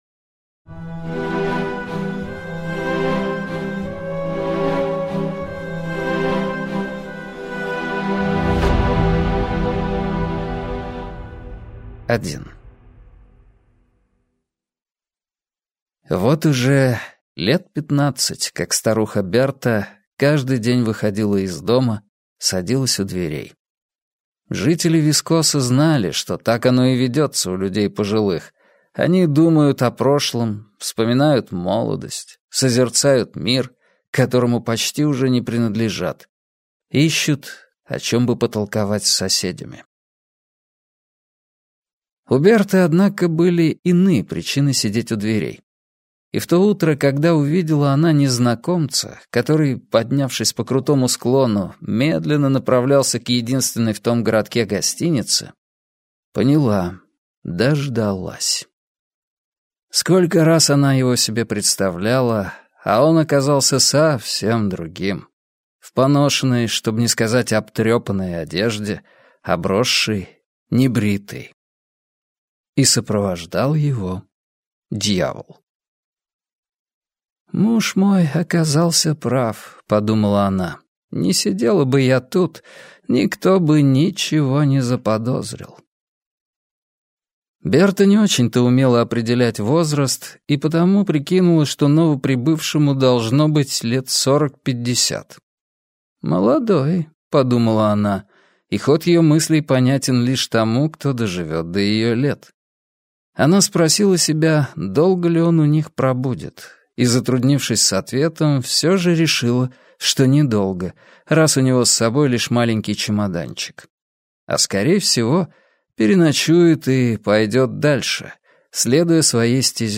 Аудиокнига Дьявол и сеньорита Прим - купить, скачать и слушать онлайн | КнигоПоиск